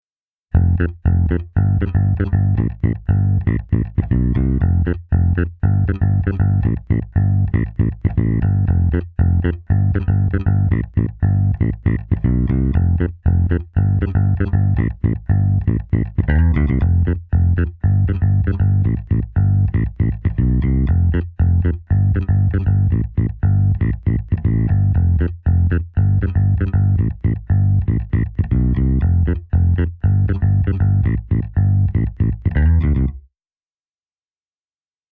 こちらはベースのみのサンプルです。
今までと同じく、前半がMAGNETIC無し、後半の（0:17〜）がMAGNETIC有りのMixです。
EQで低音を増量させたので、箱鳴りのような音が付加され、アンプで鳴らしているような、生々しい雰囲気になりました。
TAPE COLORはModernを選択し、アタックを強調しています。
それに比べると、最初の方はDIで録りました、というようなハッキリとした音色です。